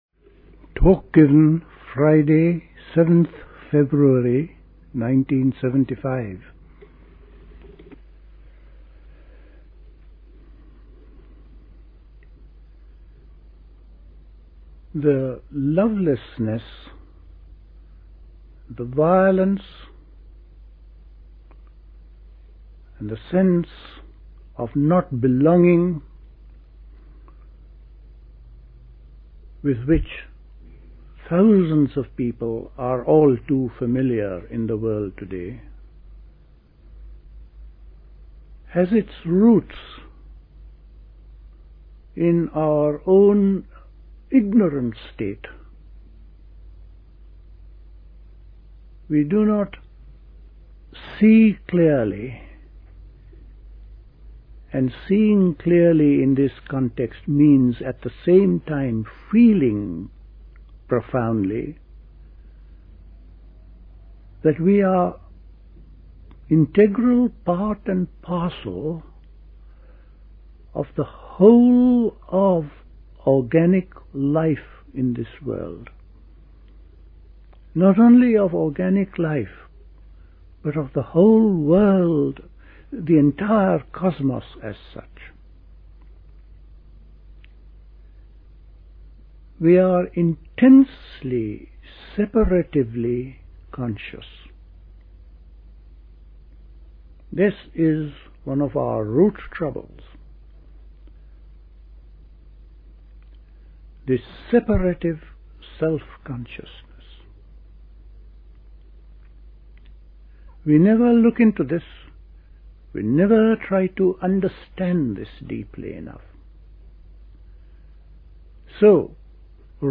A talk given
at Dilkusha, Forest Hill, London on 7th February 1975